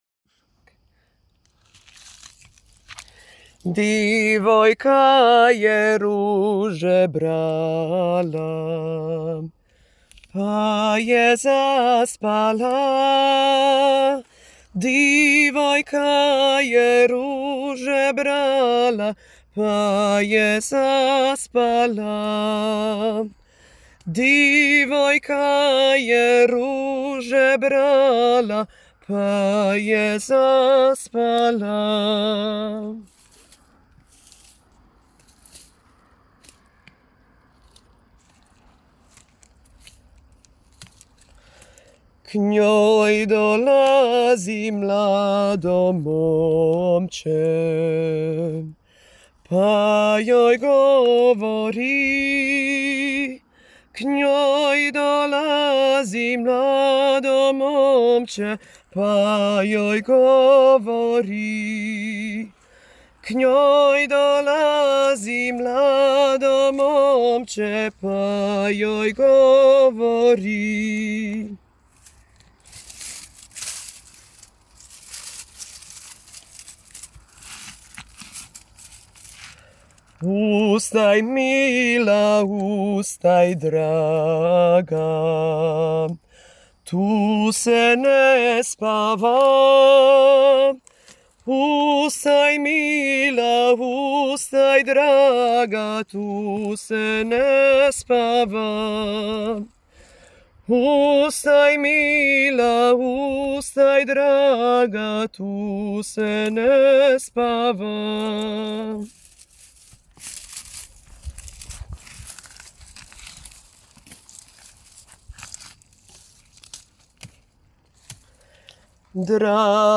Designed as a collection of traditional tunes in modern a cappella arrangements, the songs convey women’s labor and daily routines with warmth and humor.